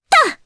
Laias-Vox_Jump_jp.wav